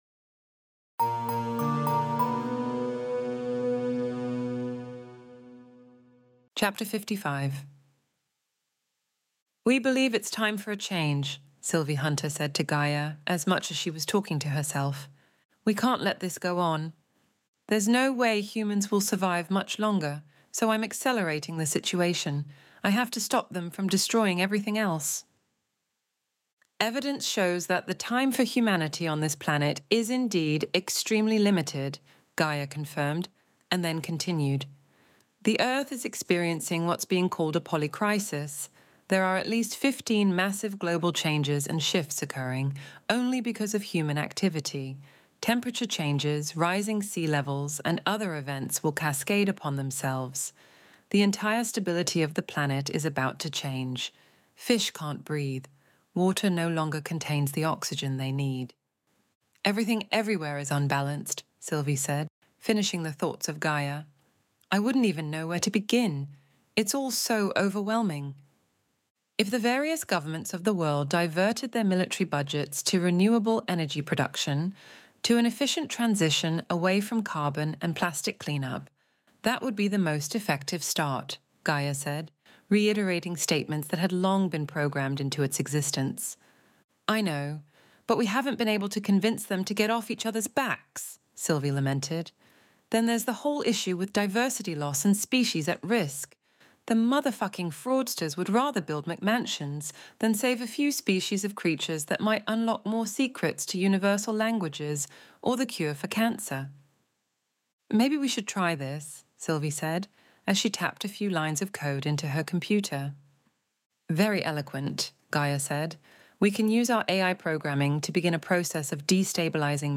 Extinction Event Audiobook Chapter 55